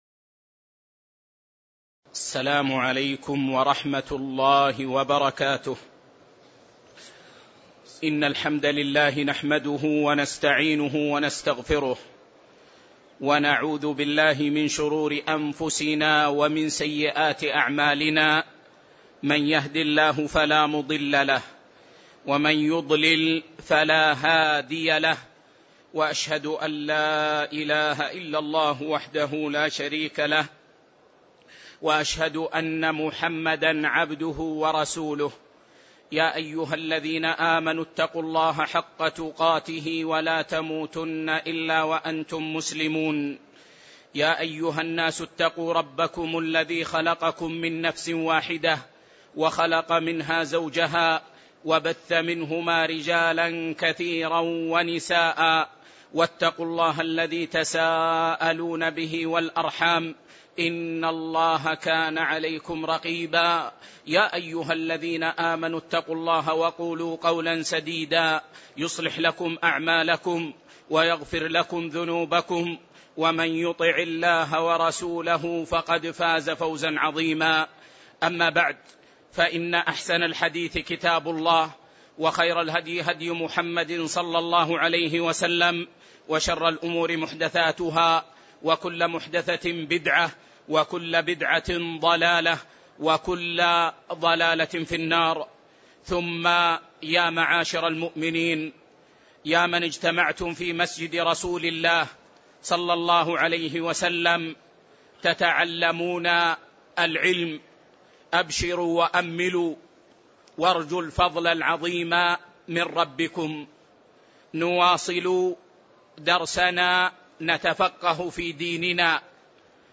تاريخ النشر ٢٢ جمادى الآخرة ١٤٣٧ هـ المكان: المسجد النبوي الشيخ